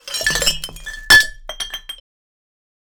Glass multifall on carpet 02
Glass_multi_fall_on_carpet_01.wav